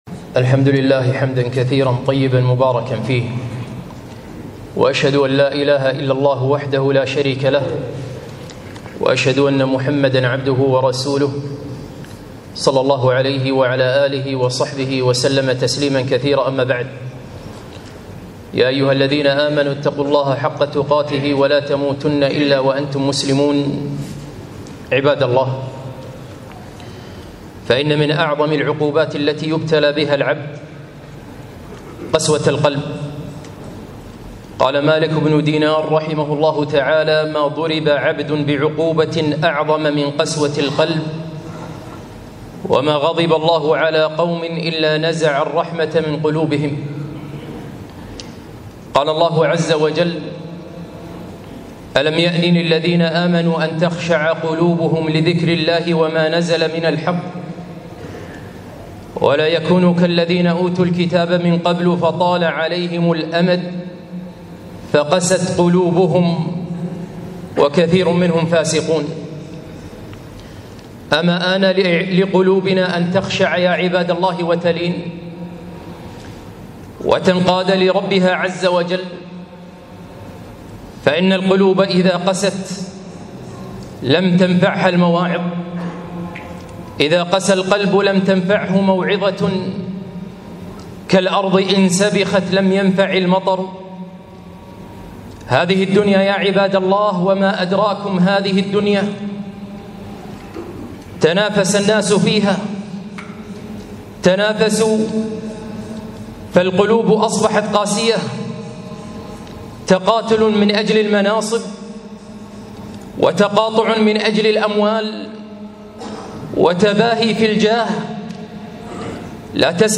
خطبة - هل اعتبرنا؟!